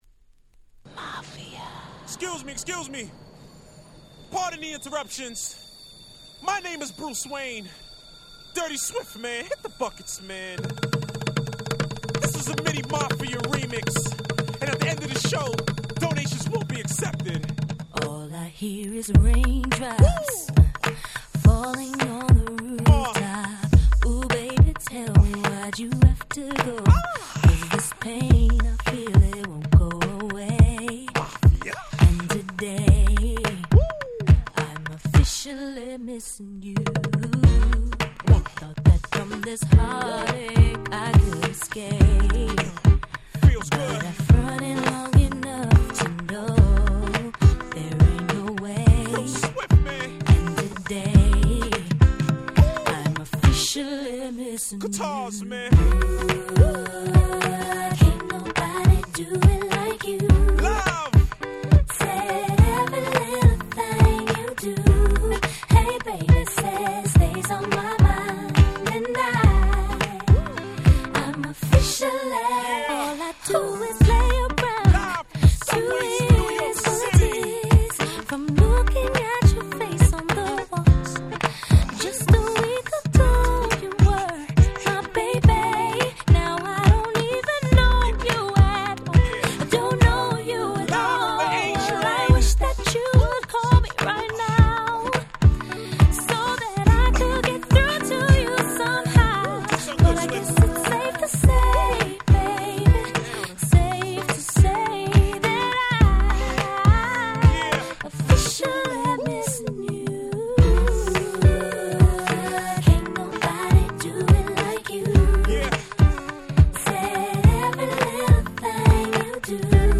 03' Super Nice R&B !!